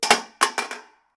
Tirar una lata a la papelera
Sonidos: Oficina
Sonidos: Hogar